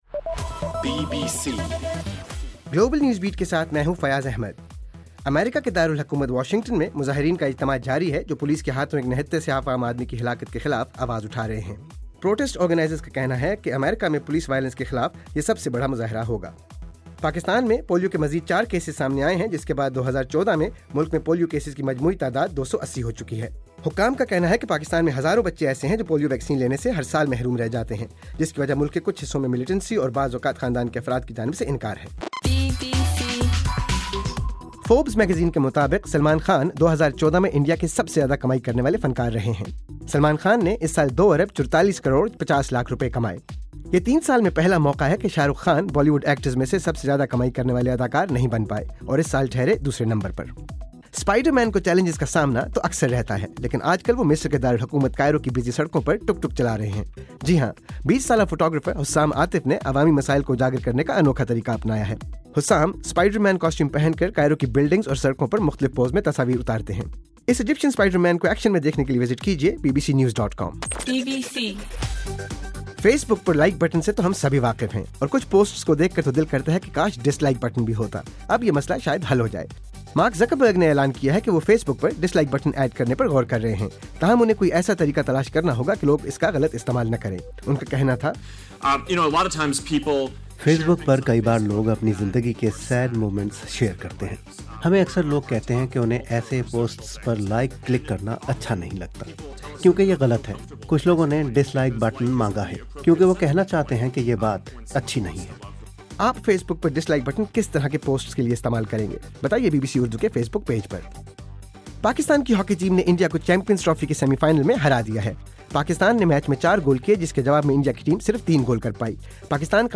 دسمبر 13: رات 11 بجے کا گلوبل نیوز بیٹ بُلیٹن